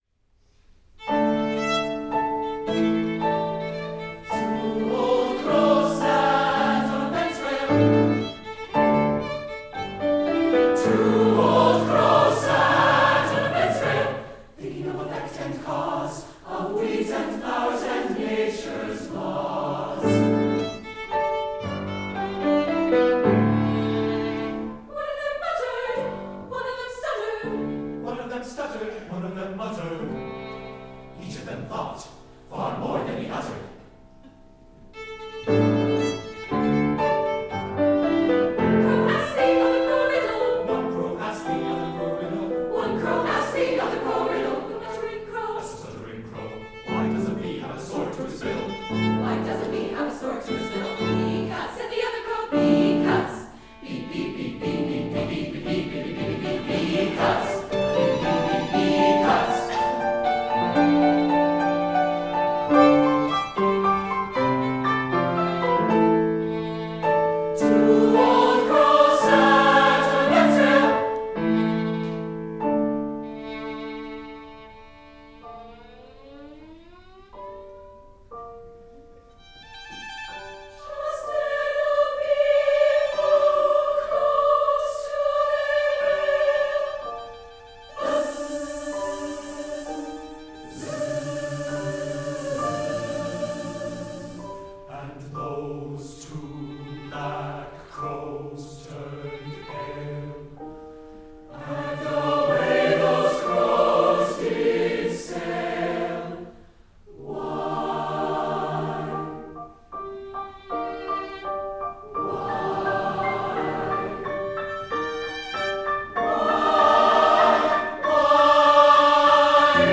TBB, violin, piano